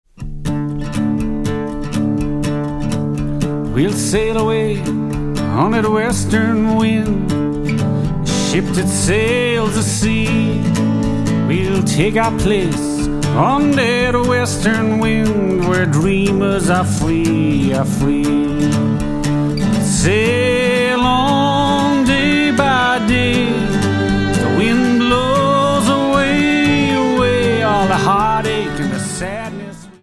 Acoustic Singer/Songwriter